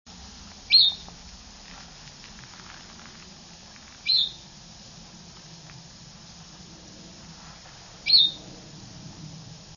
Yellow-bellied Flycatcher
Slide Mountain summit, Catskills Wildlife Preserve, Ulster County, New York, 6/23/04, 12:00 p.m. (39kb) in mossy (see nest requirements below) rainforest-like habitat populated by Red Spruce and Balsam Fir.  "Pea" sound described by Baird in Birds of America.
flycatcher_yellow-bellied_831.wav